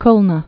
(klnə)